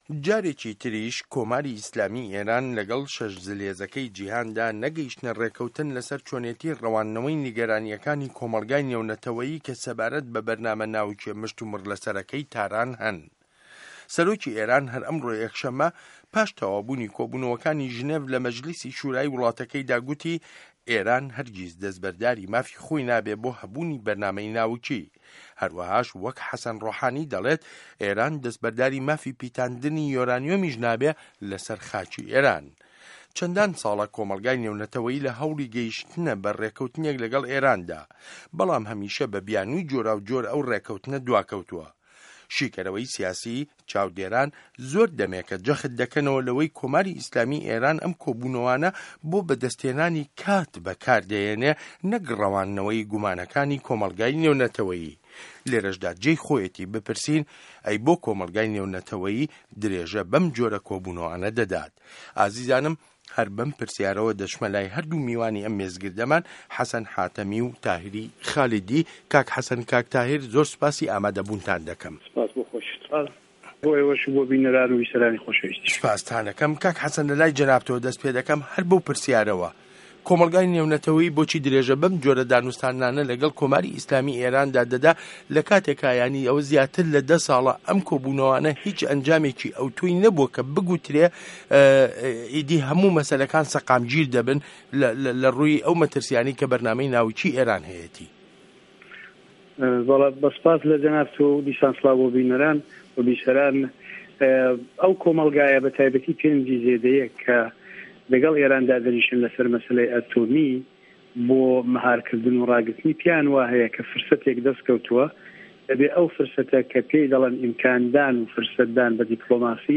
مێزگرد: ئێران سوره‌ له‌سه‌ر پیتاندنی یۆرانیۆم